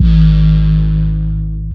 808 - REM.wav